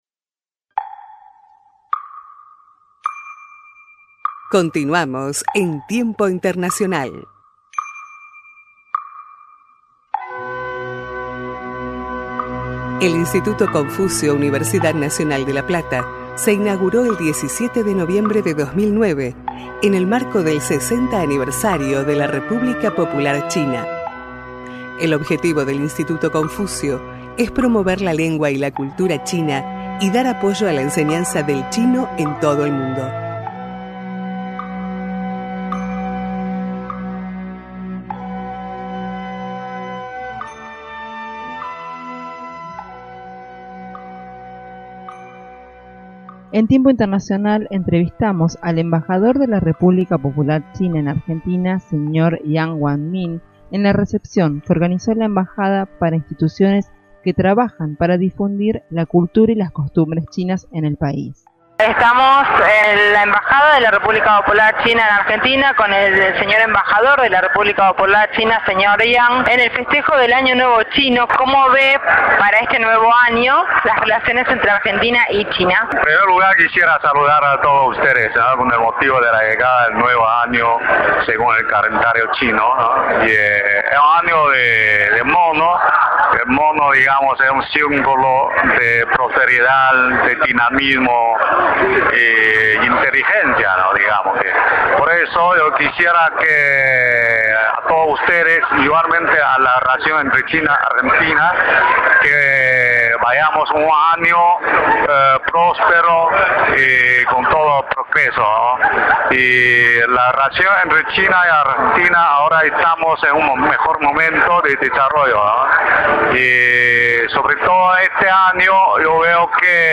Estado Islámico.Entrevista